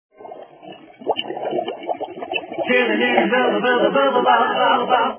Bubbles
bubbles.wav